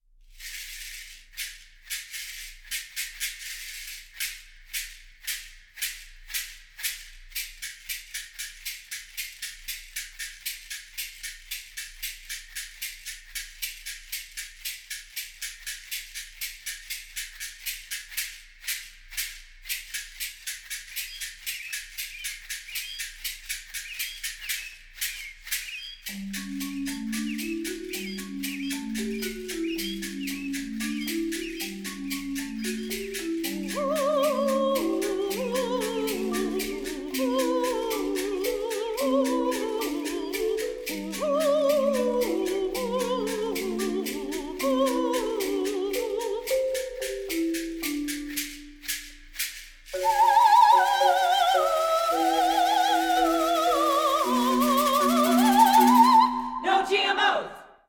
piano
percussion